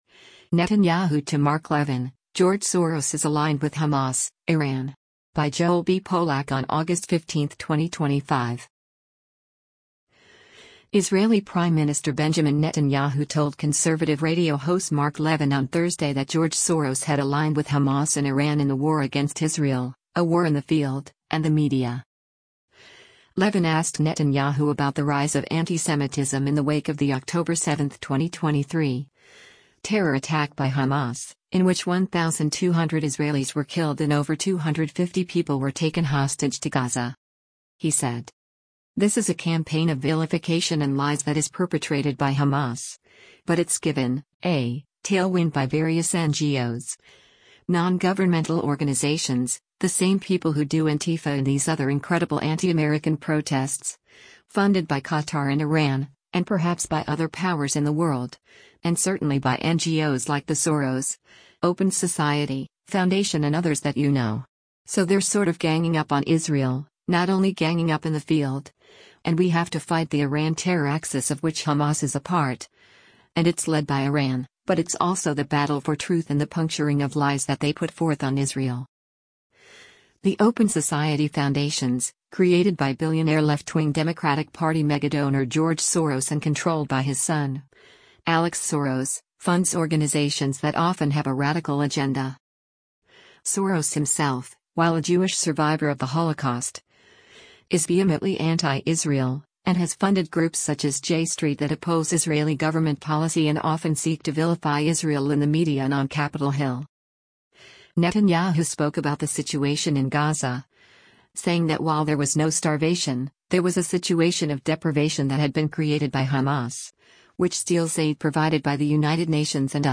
Israeli Prime Minister Benjamin Netanyahu told conservative radio host Mark Levin on Thursday that George Soros had aligned with Hamas and Iran in the war against Israel — a war in the field, and the media.